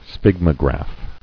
[sphyg·mo·graph]